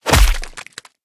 watermelon_impact_00.ogg